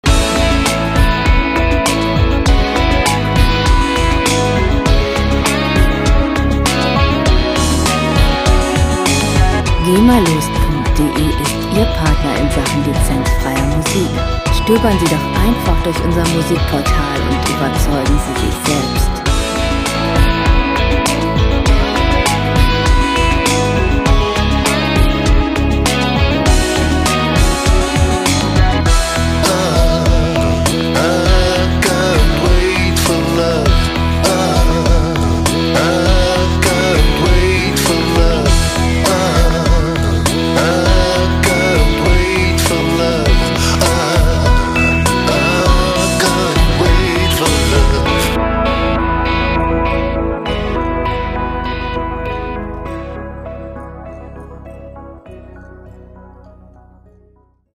gemafreie Pop Musik
Musikstil: Pop
Tempo: 100 bpm
Tonart: A-Dur
Charakter: emotional, tiefgründig
Instrumentierung: Synthie, E-Bass, Drums, Gitarre, Vocals